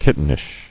[kit·ten·ish]